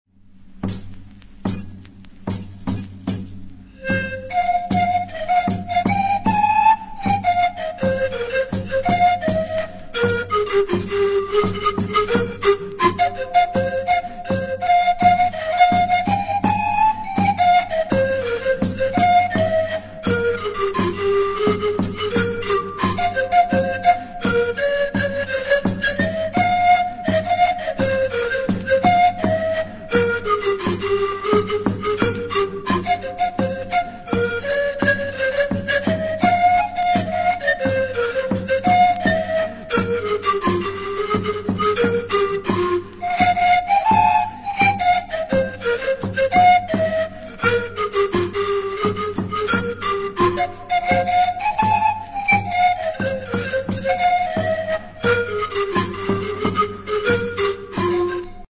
Aerophones -> Flutes -> Pan flute
Special instrumental.